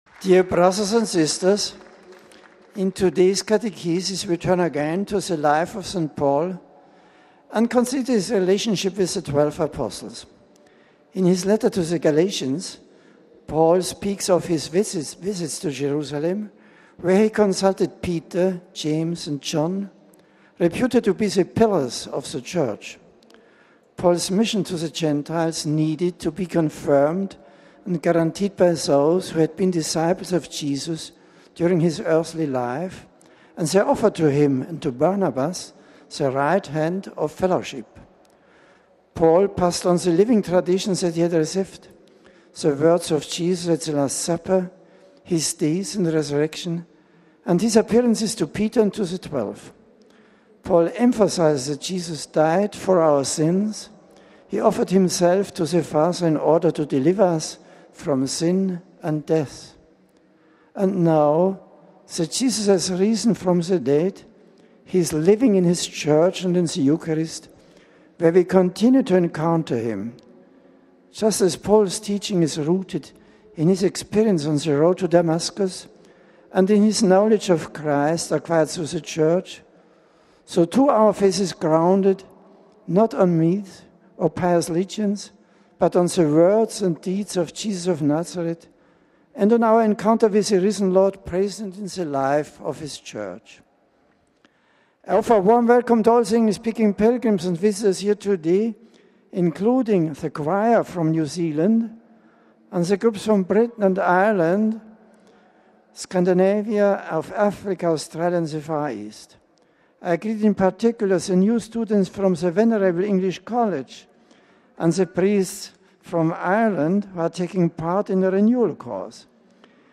Pope Benedict in English - Weekly General Audience